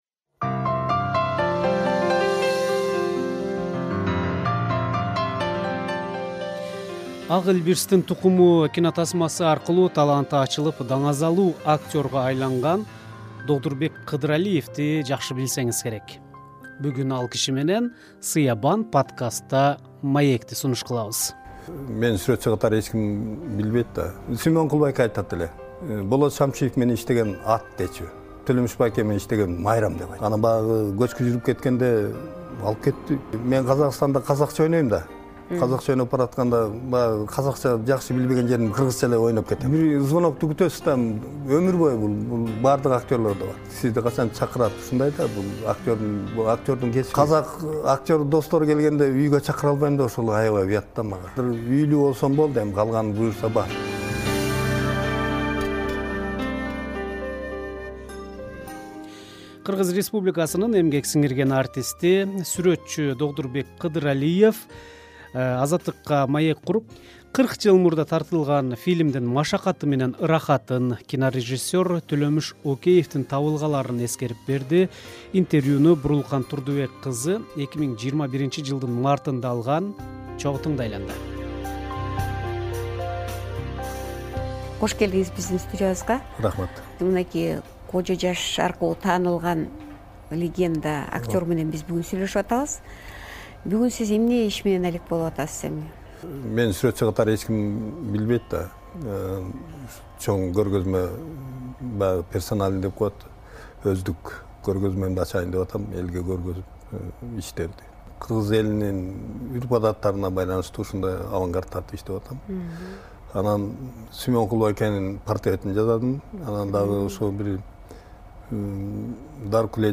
Интервью 2021-жылдын мартында жазылган.